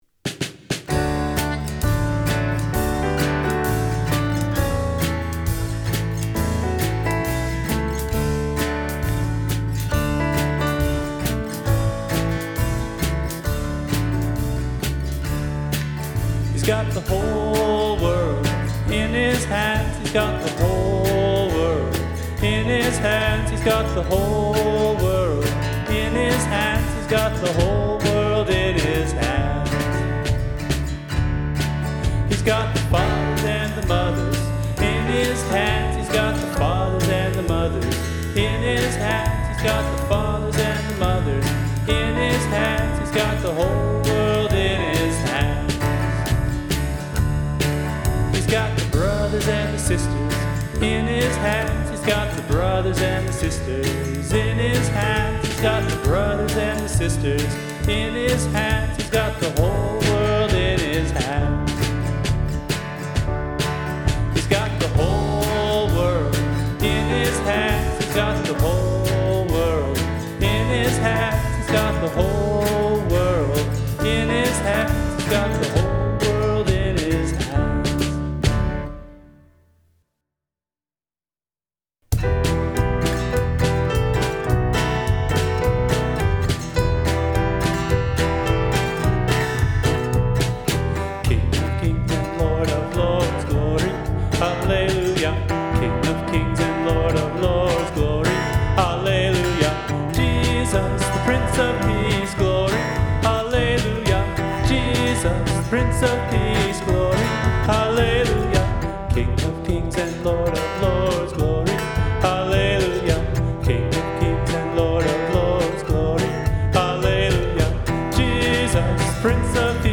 Special worship music for Pastoral Search Prayer Night.
Audio begins with 3 worship songs specifically geared toward kids, after which there is other worship music.